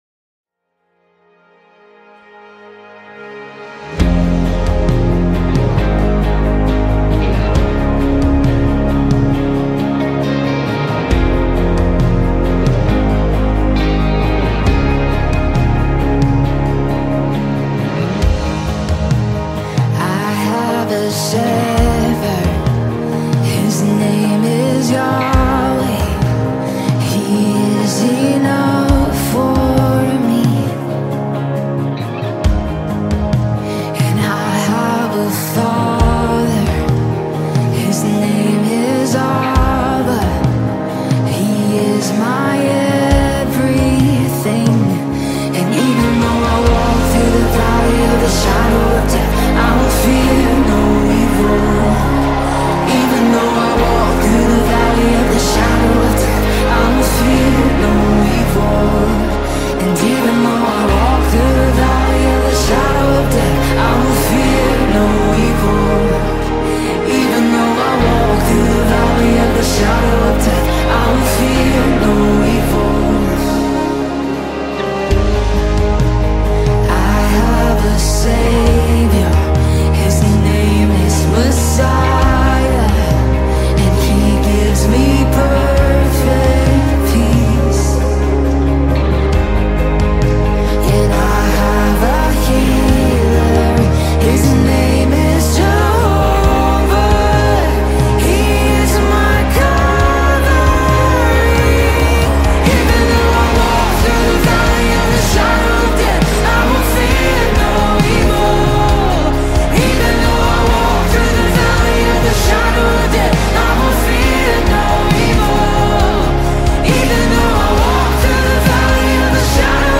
песня
660 просмотров 435 прослушиваний 17 скачиваний BPM: 67